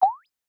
Text-Message-Acknowledgement-QuestionMark.mp3